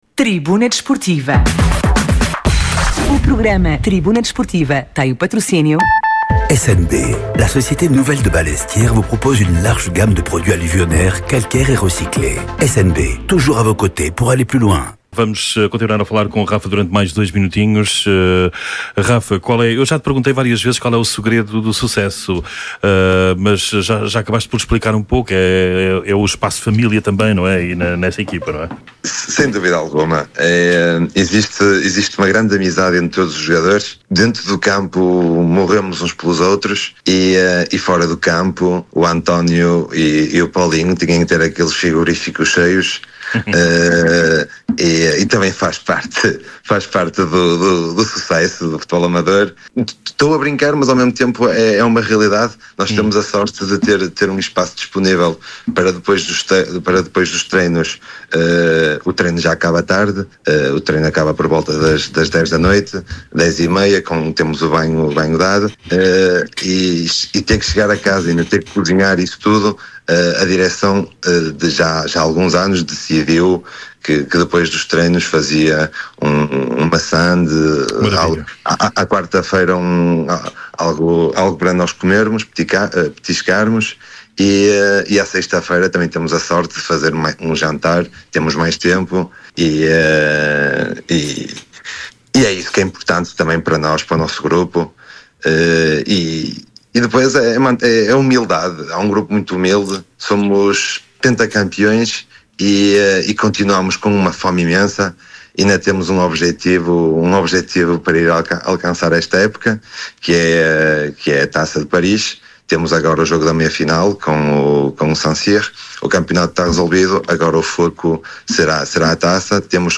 Atualidade Desportiva, Entrevistas.
Tribuna Desportiva é um programa desportivo da Rádio Alfa às Segundas-feiras, entre as 21h e as 23h.